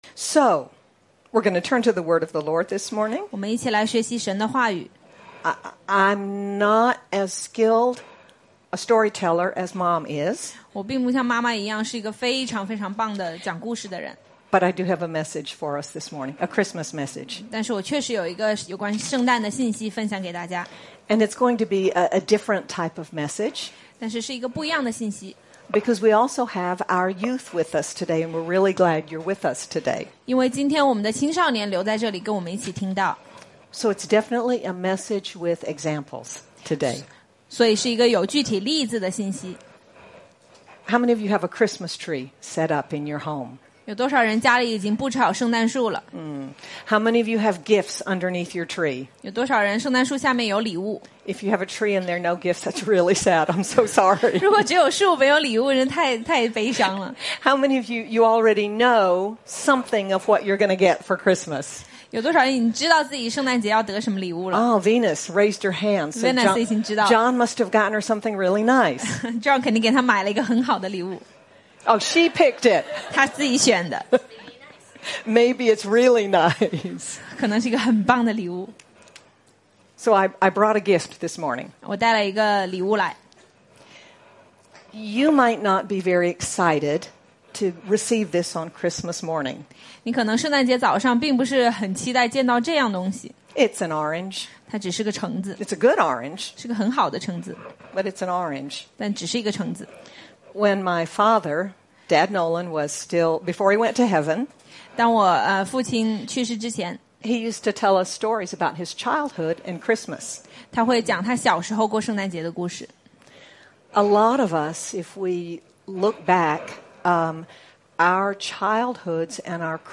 Dec 27, 2025 Jesus—The Gift Too Wonderful for Words MP3 SUBSCRIBE on iTunes(Podcast) Notes Discussion Sermons in this Series On this fourth Sunday of Advent, we look at Jesus, God’s gift to us that is too wonderful for words. Sermon By